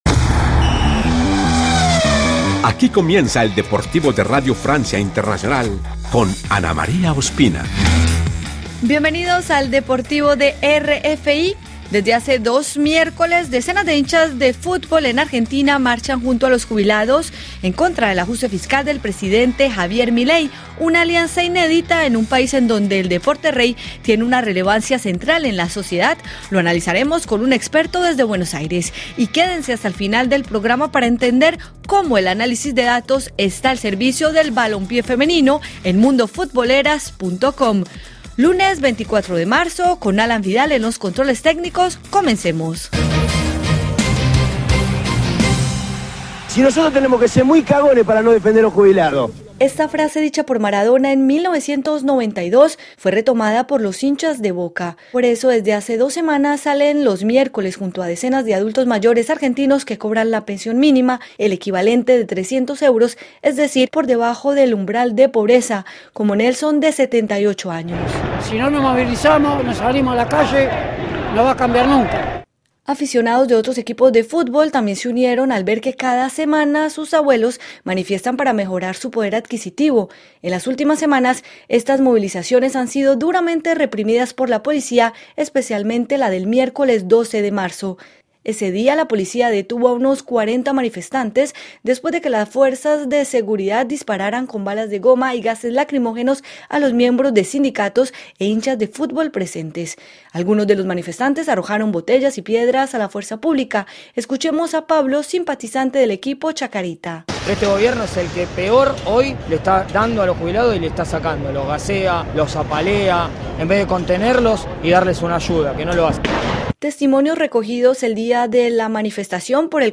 Esta edición del magazín deportivo de RFI estuvo dedicada a la epidemia de lesiones en el fútbol europeo.